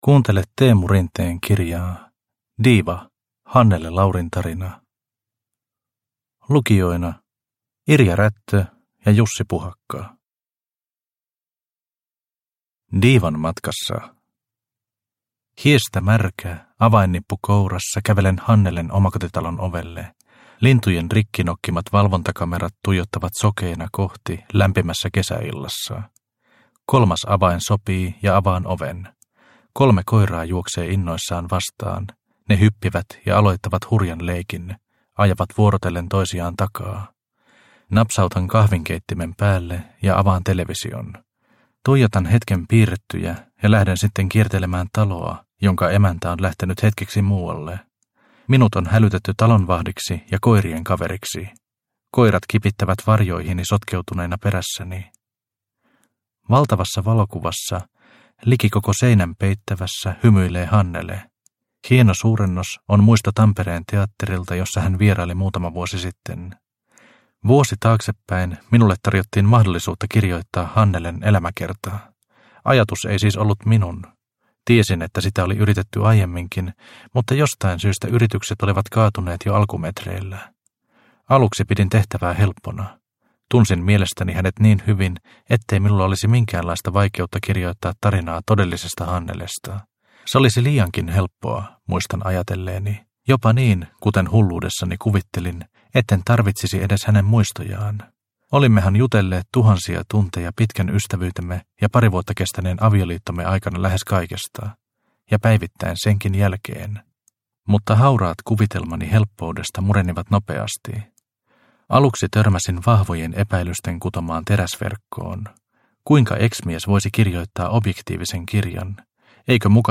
Diiva – Ljudbok – Laddas ner